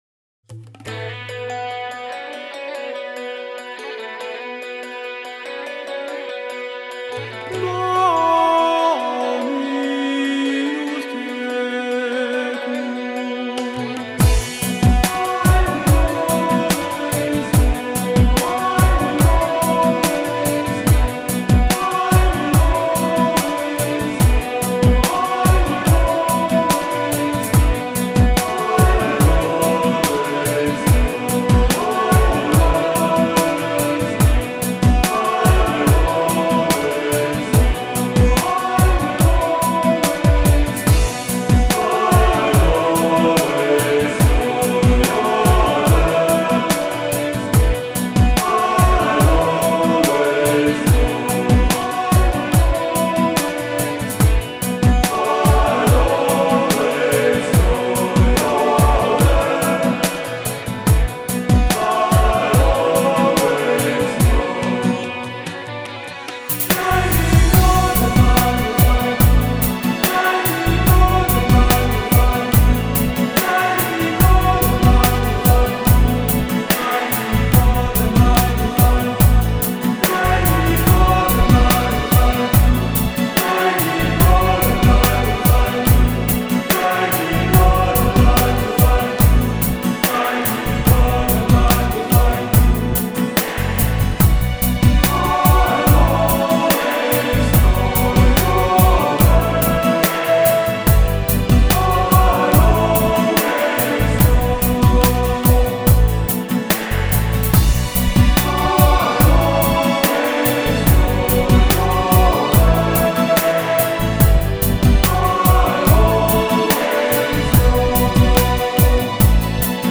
欧洲天籁合唱